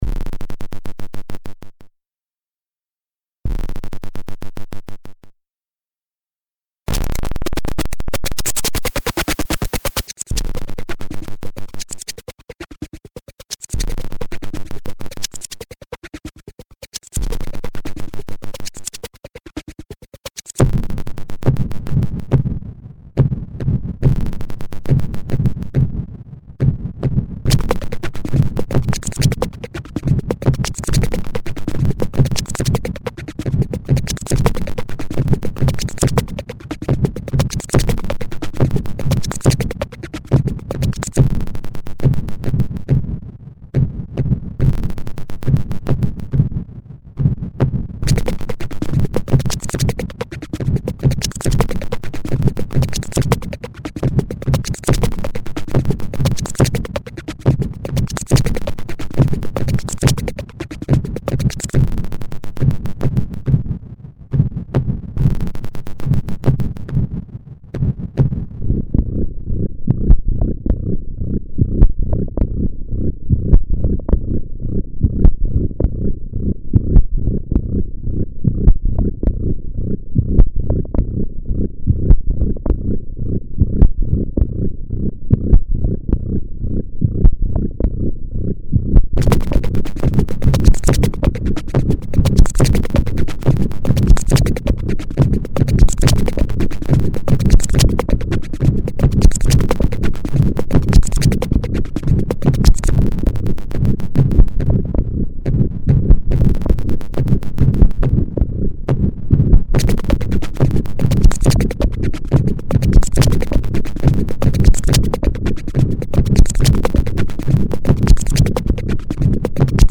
is a collection of lo-fi electronic dance tracks.
This is raw clicks'n'cuts for the new millennium.